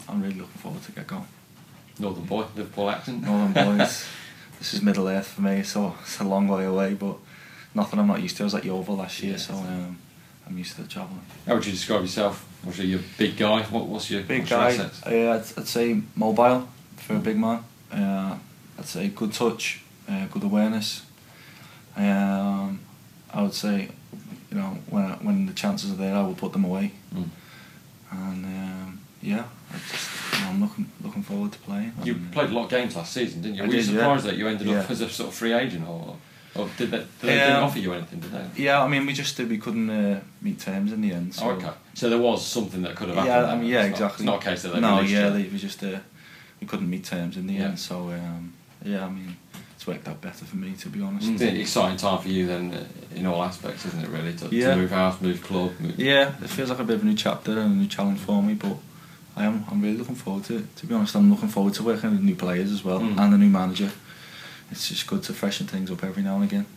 INTERVIEW: Tom Eaves, Gillingham FC - 23/06/2017